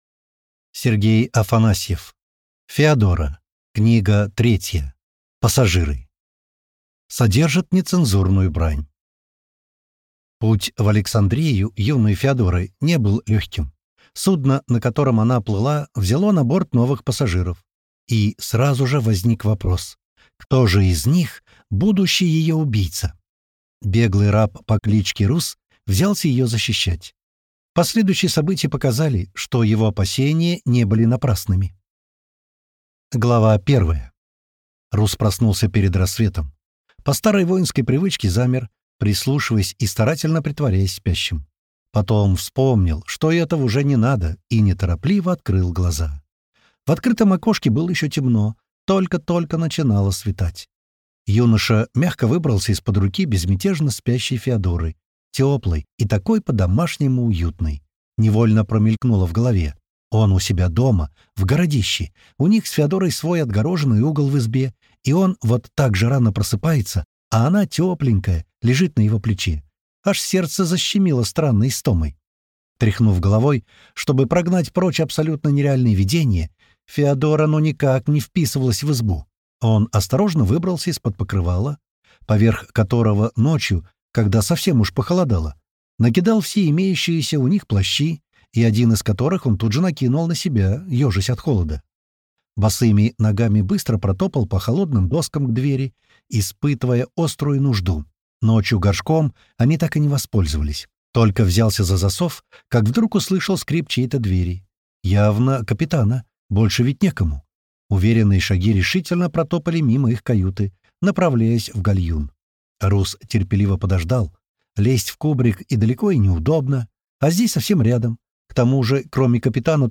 Аудиокнига Феодора-3. Пассажиры | Библиотека аудиокниг